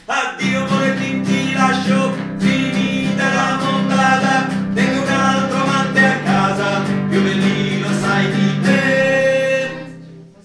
Addio_Morettin_Tenor.mp3